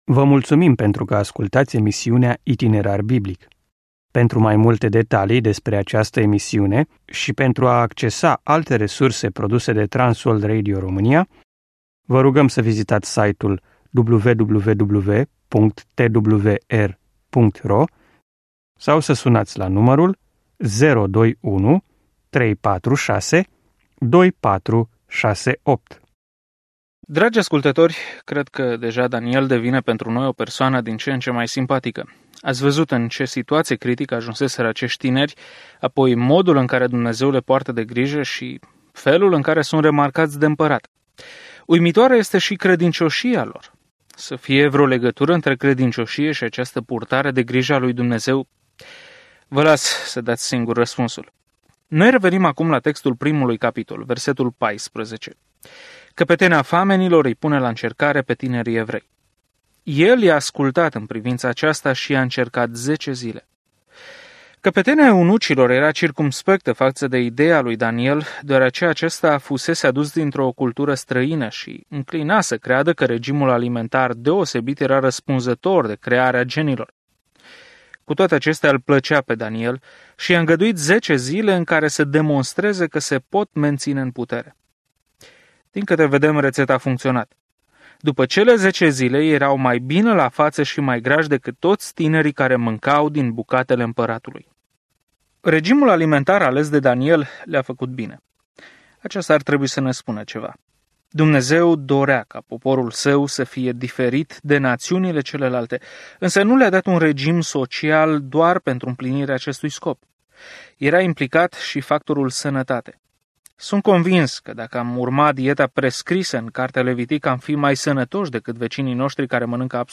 Scriptura Daniel 1:14-21 Daniel 2:1-14 Ziua 2 Începe acest plan Ziua 4 Despre acest plan Cartea lui Daniel este atât o biografie a unui om care a crezut pe Dumnezeu, cât și o viziune profetică despre cine va conduce lumea în cele din urmă. Călătoriți zilnic prin Daniel în timp ce ascultați studiul audio și citiți versete selectate din Cuvântul lui Dumnezeu.